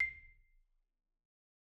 Marimba_hit_Outrigger_C6_loud_01.wav